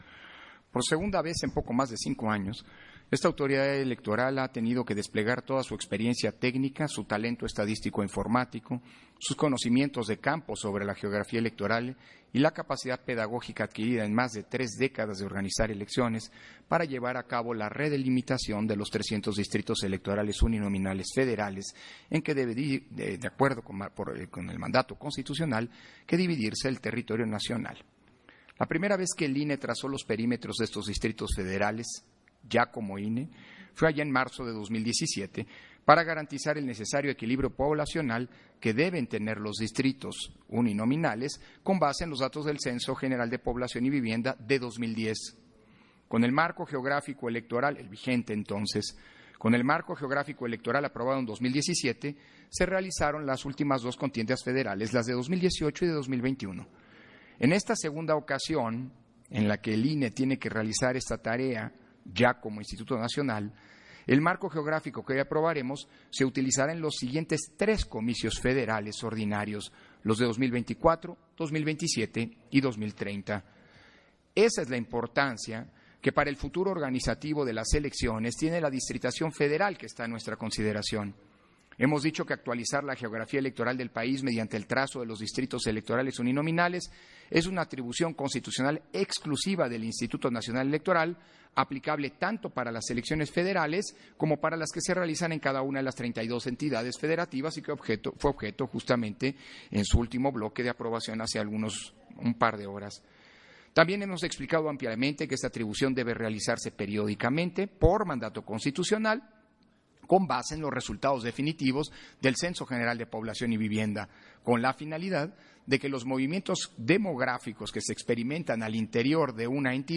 141222_AUDIO_INTERVENCIÓN-CONSEJERO-PDTE.-CÓRDOVA-PUNTO-24-SESIÓN-EXT. - Central Electoral